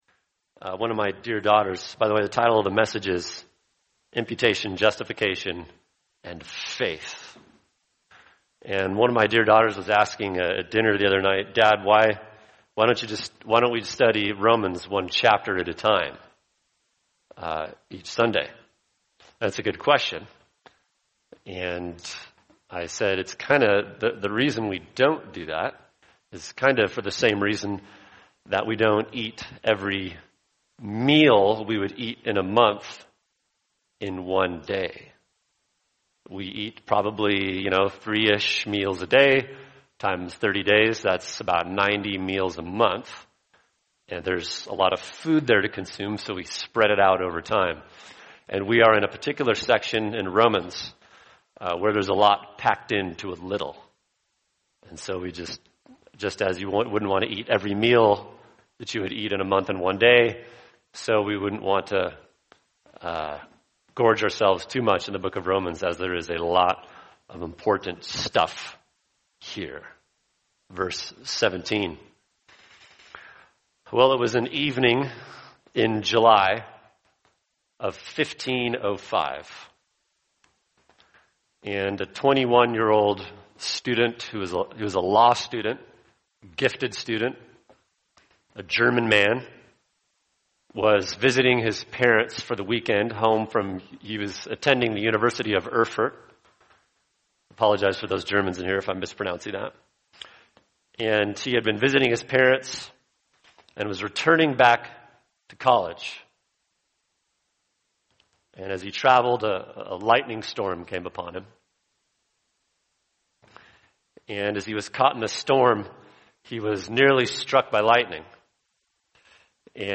[sermon] Romans 1:17 Imputation, Justification and Faith | Cornerstone Church - Jackson Hole